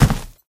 sounds / material / human / step / new_wood1.ogg
new_wood1.ogg